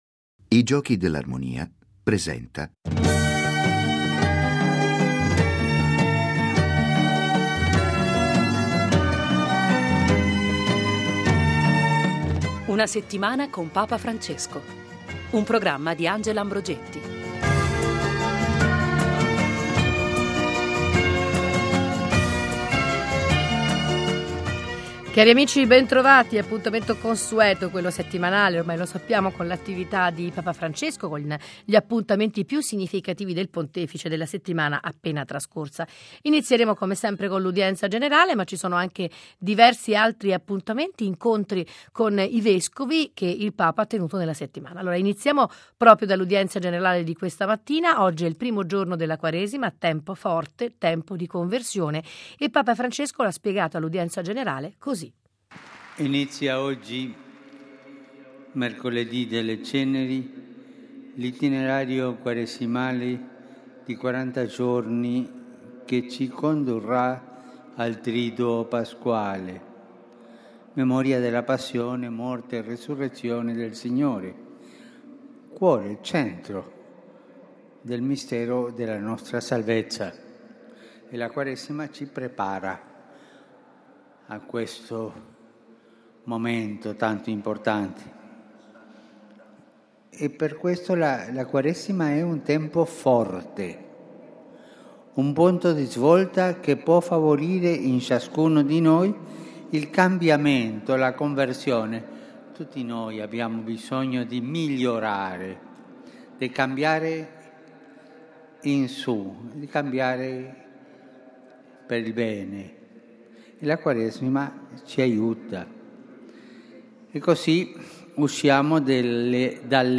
Qualche ospite e la lettura dei giornali, ma soprattutto la voce del Papa a partire dall’appuntamento del mercoledì per l’Udienza Generale.